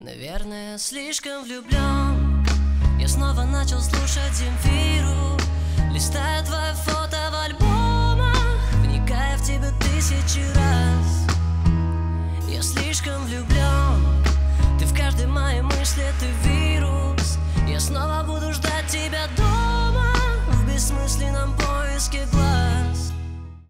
бесплатный рингтон в виде самого яркого фрагмента из песни
Рок Металл